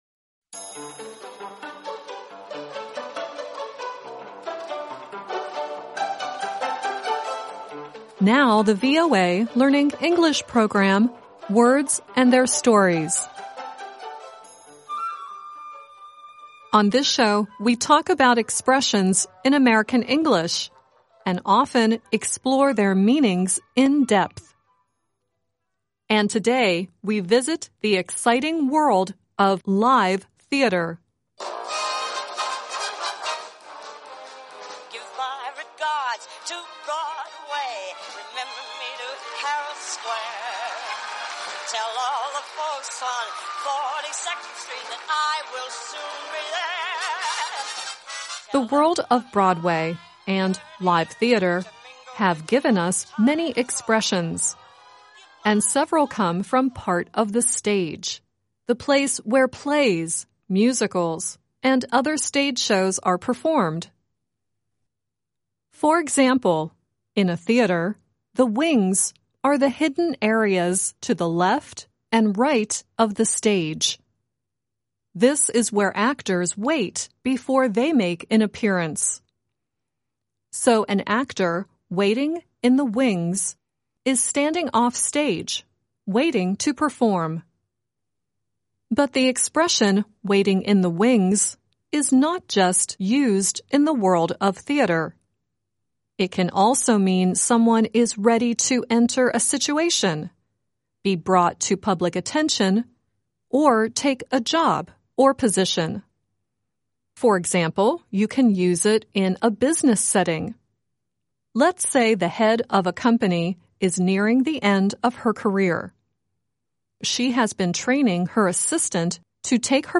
The songs in order of appearance are: Judy Garland singing “Give My Regards to Broadway,” cast members of the musical Curtains singing “Show People” and Ben E. King sings “Stand By Me” at the end.